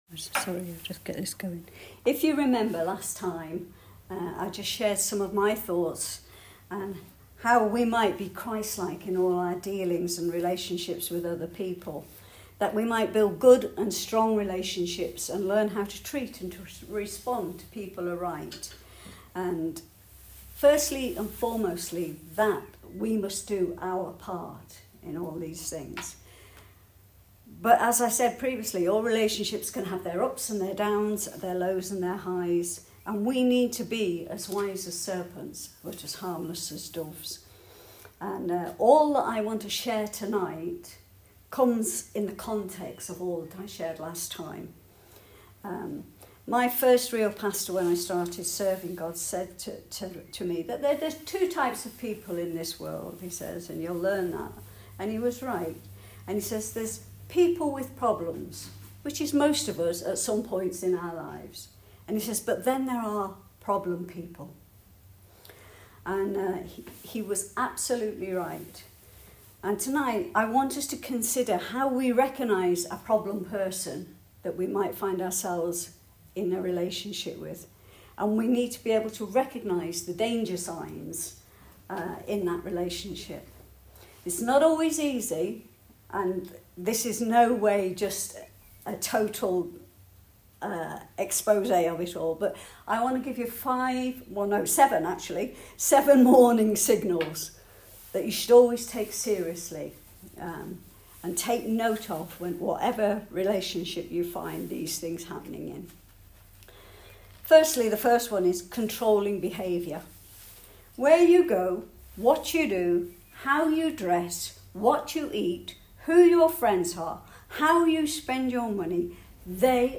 An episode by Calvary Chapel Warrington Sermons